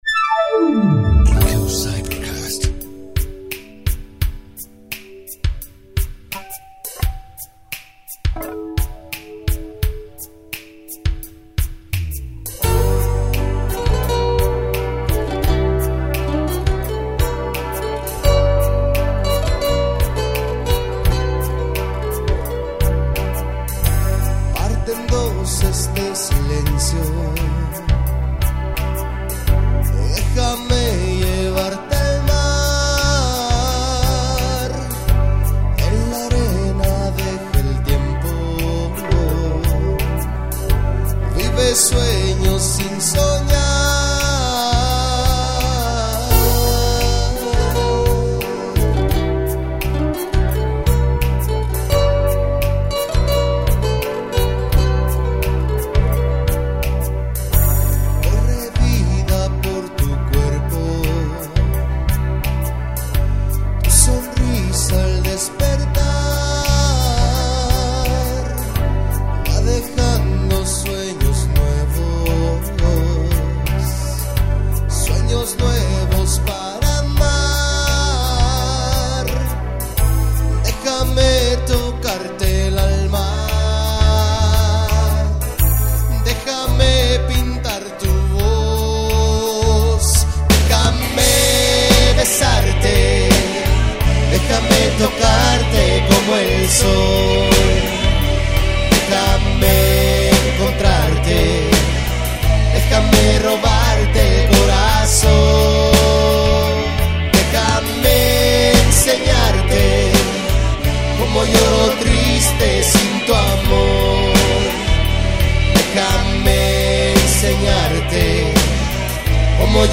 Voz y Coros